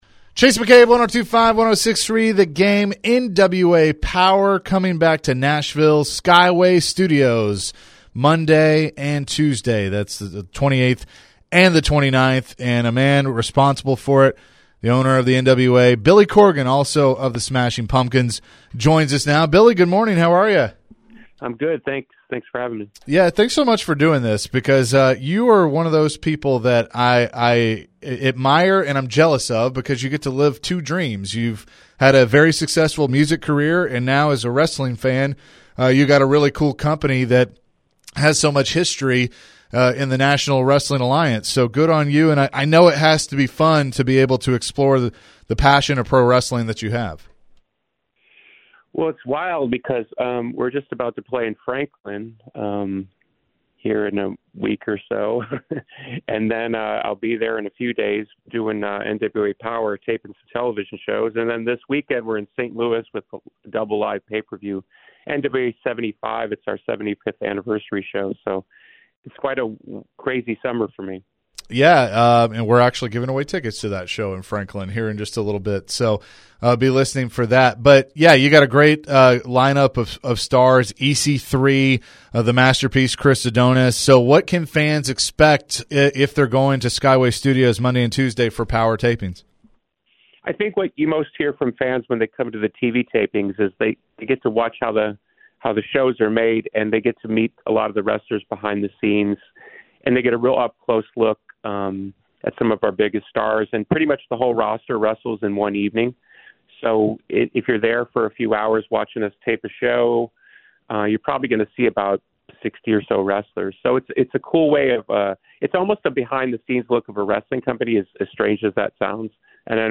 Billy Corgan Interview (8-23-23)